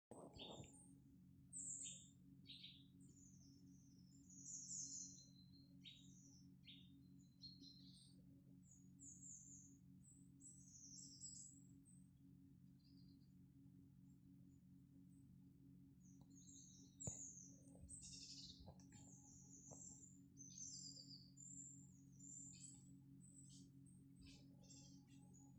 Nuthatch, Sitta europaea
Ziņotāja saglabāts vietas nosaukumsKrāslavas nov., Adamova
StatusVoice, calls heard